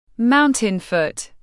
Mountain foot /ˈmaʊntən fʊt/